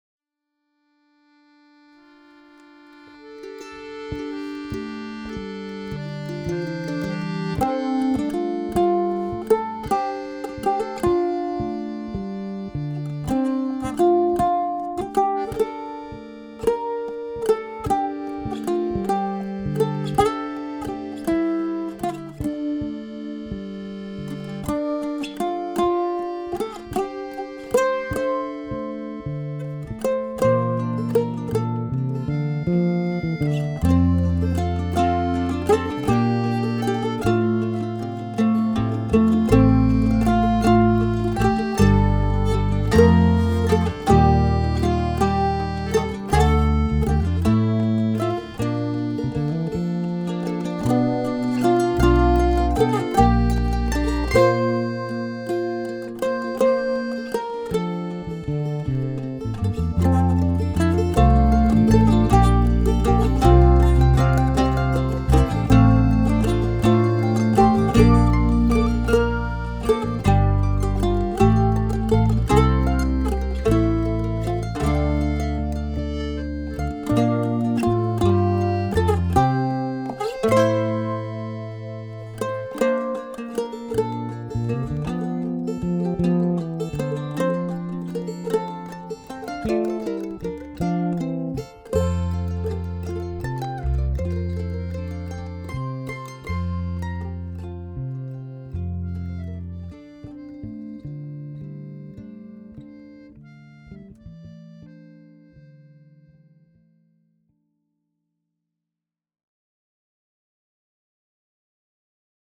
Genre : morceau instrumental
Instrument de musique : mandole ; mandoline ; guitare basse électrique ; accordéon